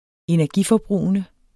Udtale [ -fʌˌbʁuˀənə ]